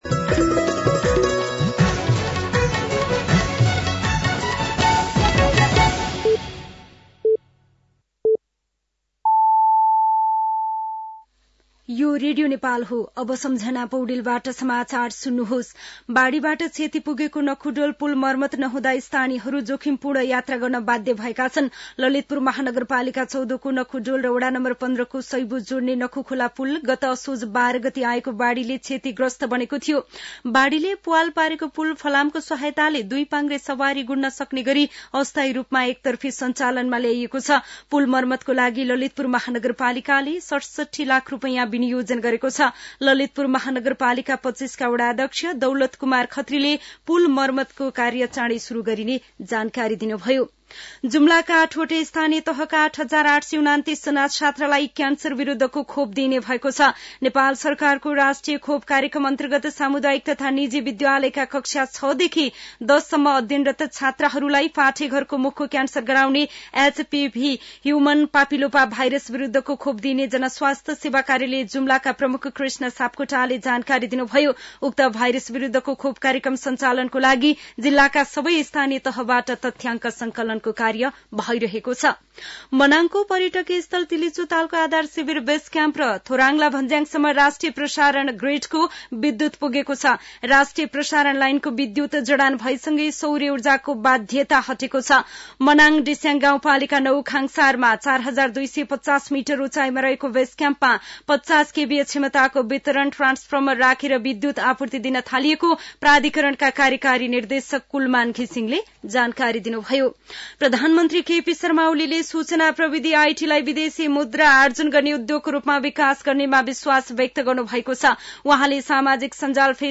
साँझ ५ बजेको नेपाली समाचार : २८ पुष , २०८१
5-pm-news-2.mp3